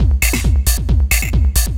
DS 135-BPM D4.wav